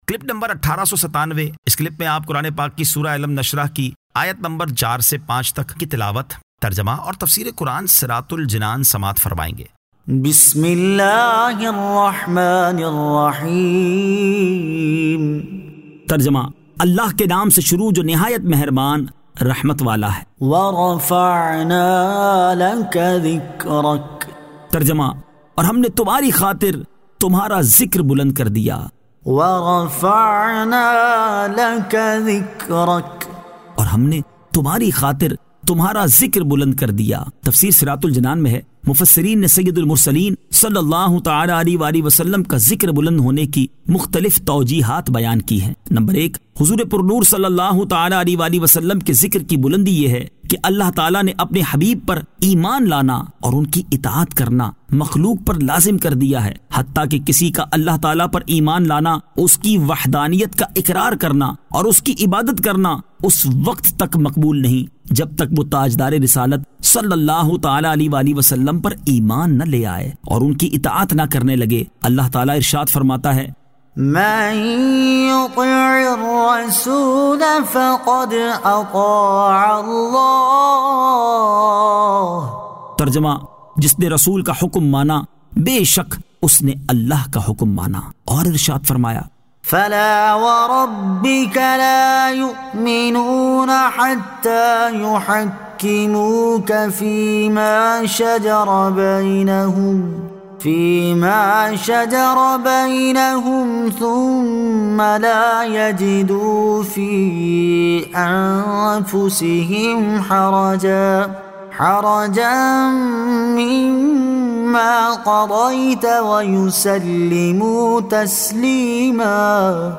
Surah Alam Nashrah 04 To 05 Tilawat , Tarjama , Tafseer